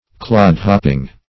Clodhopping \Clod"hop`ping\, a.